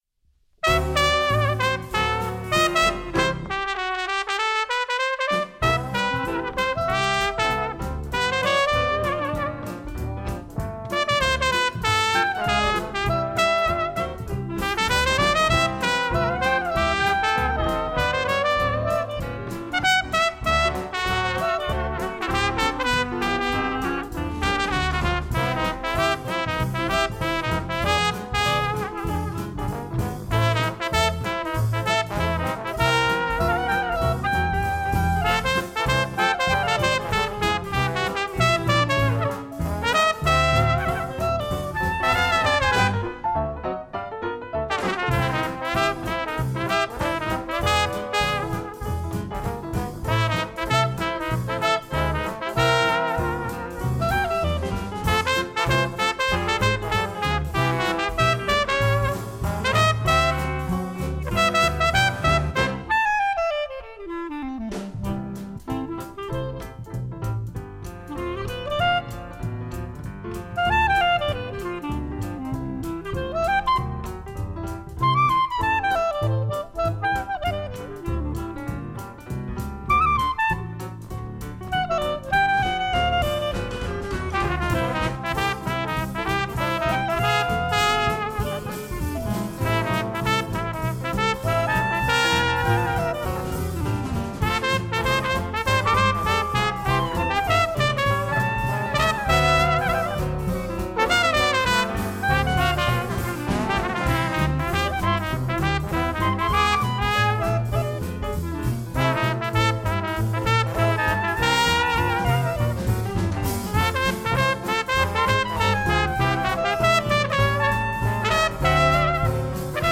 Dès les premières mesures, la magie opère.
trompette
clarinette
trombone
piano
contrebasse
batterie.